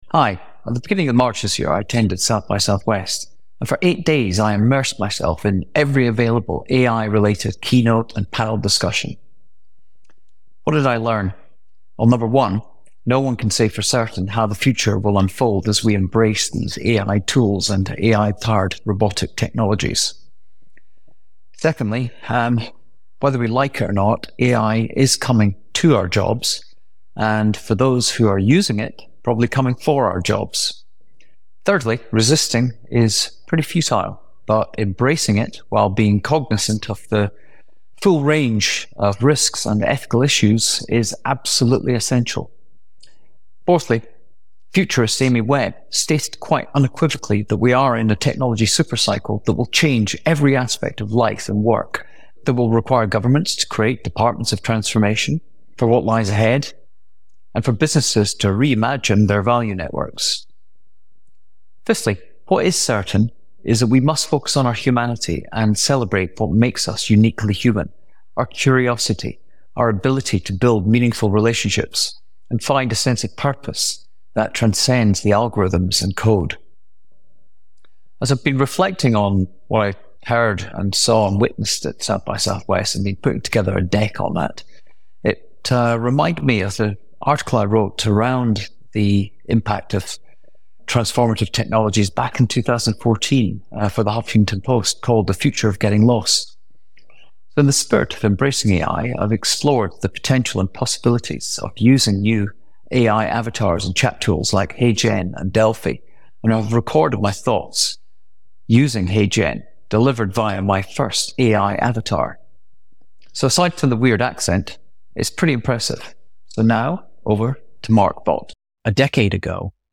Aside from the weird accent it’s pretty impressive.